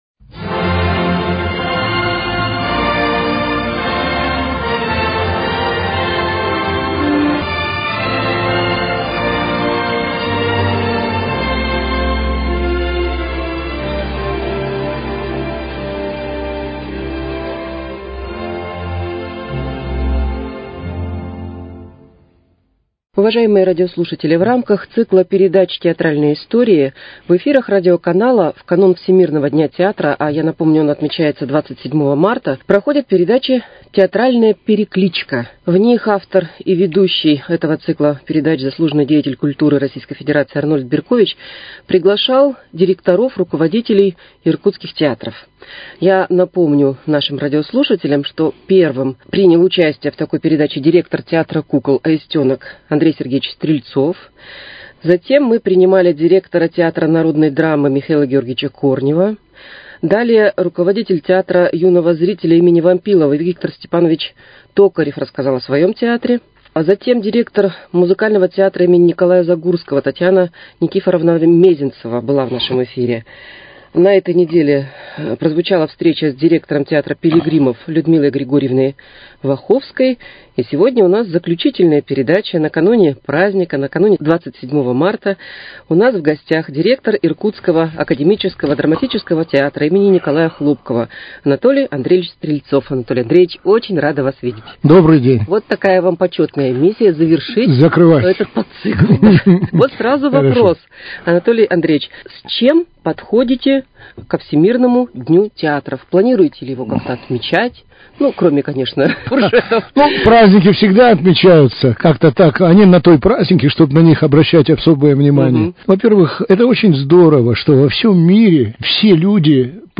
Театральные истории: Беседа